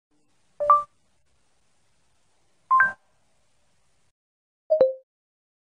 Okay Google sound effect
Thể loại: Âm thanh meme Việt Nam
Description: “Okay Google sound effect” là một hiệu ứng âm thanh ngắn, gợi nhớ âm thanh kích hoạt trợ lý ảo Google Assistant khi người dùng nói “Ok Google”...
okay-google-sound-effect-www_tiengdong_com.mp3